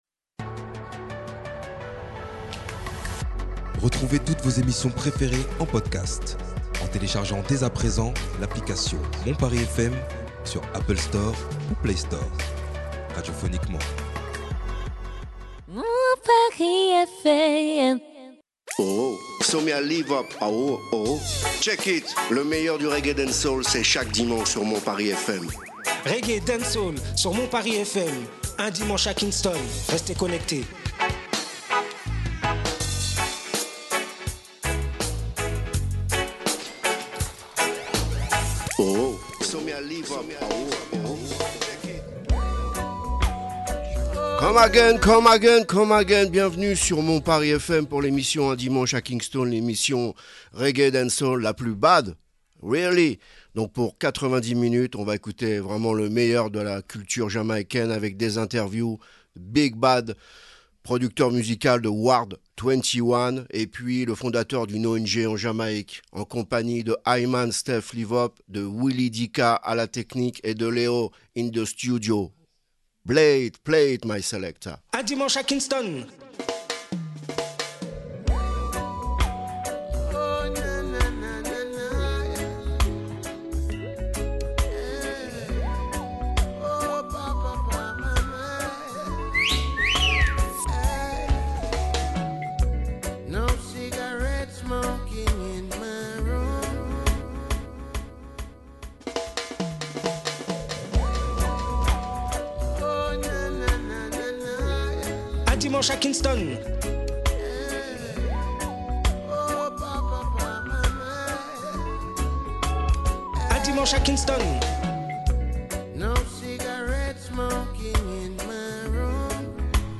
Cette semaine, sélectionmusicale de premier choix + 2 ITW exclusives de :